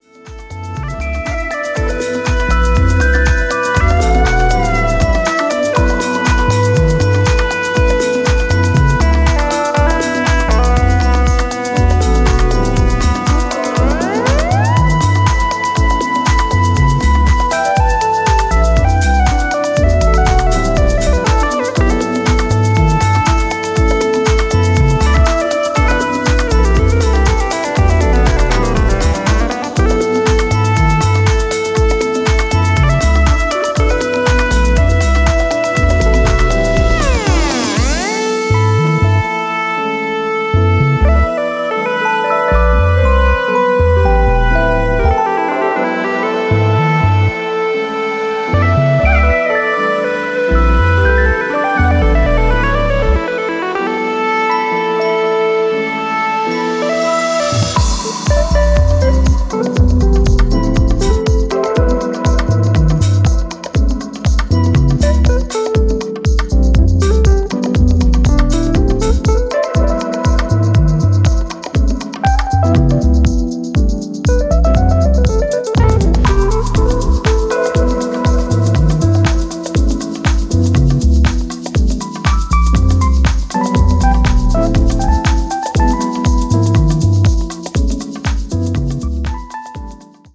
ここでは、自身の手による鍵盤やフルートなどのジャジーな演奏を用いながら温かくしなやかなフュージョン・ハウスを展開。